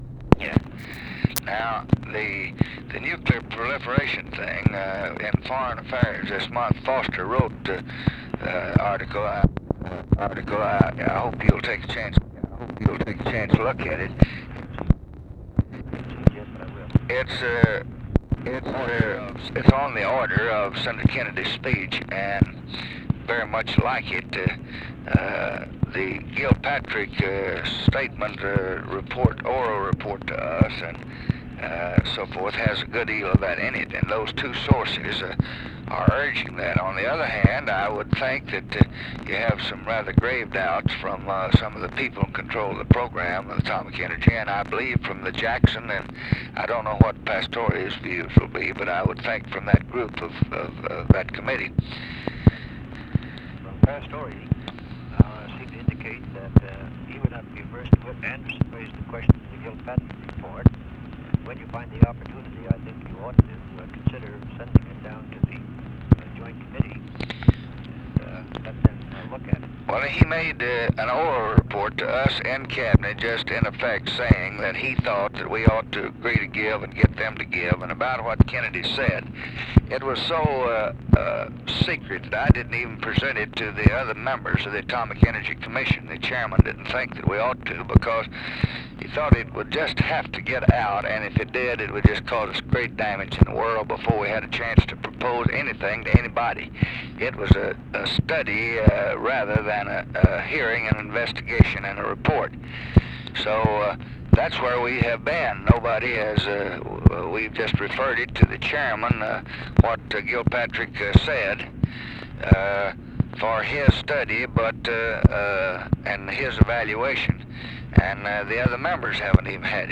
Conversation with MIKE MANSFIELD, June 26, 1965
Secret White House Tapes